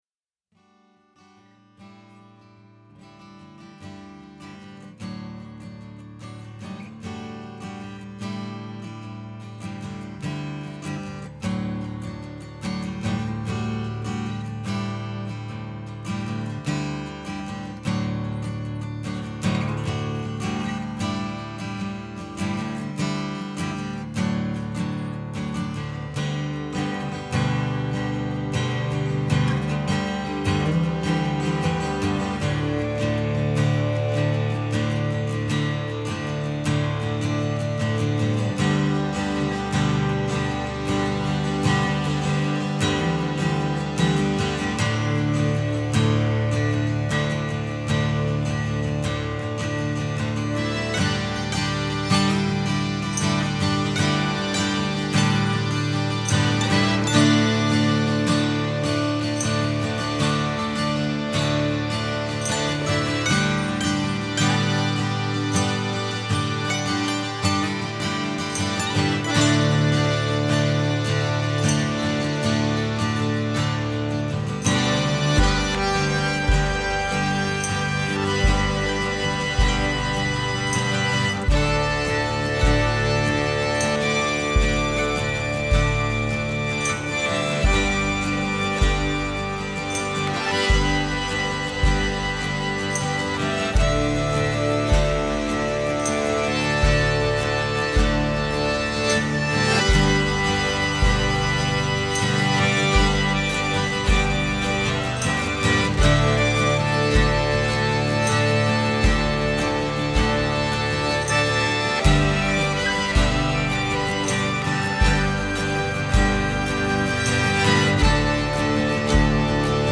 Falls Church, VA - May 2004
Guitar, Mandolin
Percussion
Accordion
Upright Bass